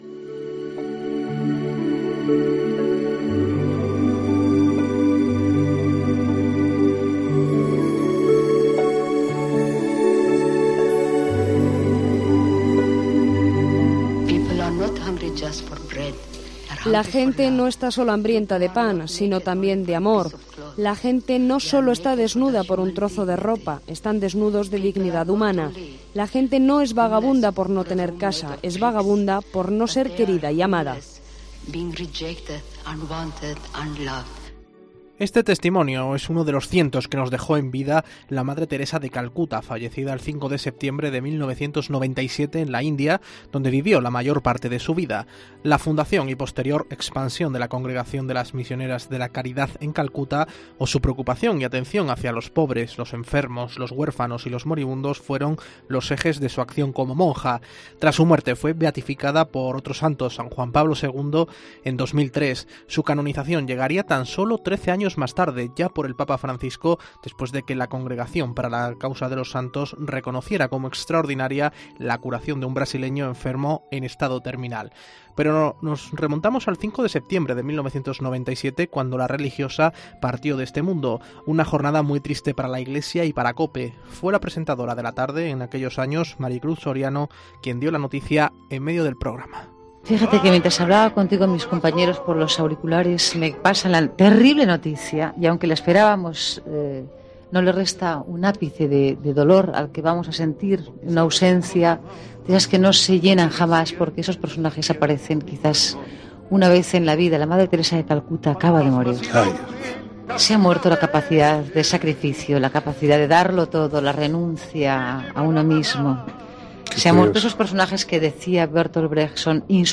Allí se encontraba la mítica corresponsal de COPE en Roma, Paloma Gómez Borrero. En ‘La Tarde’, la periodista fallecida en 2017 informaba que Juan Pablo II ya había recibido la triste nueva.